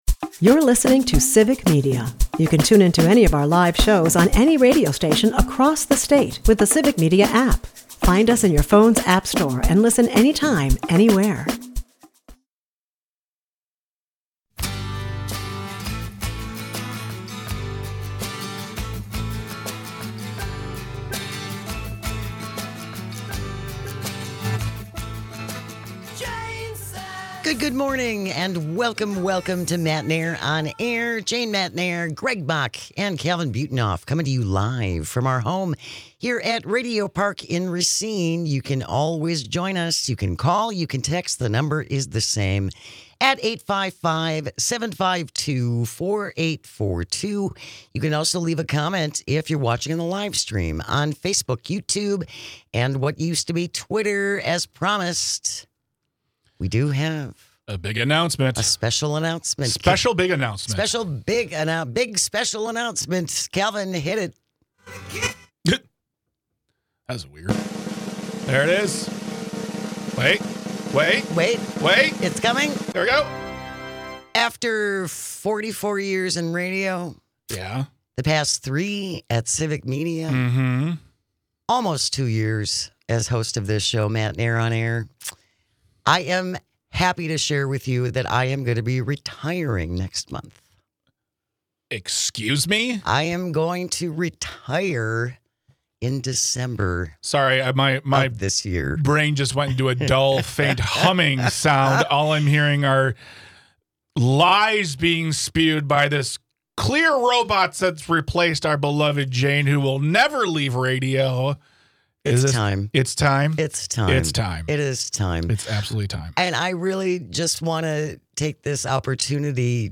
We have a great show curated for you today with some of our favorite moments from this week including a huge announcement!!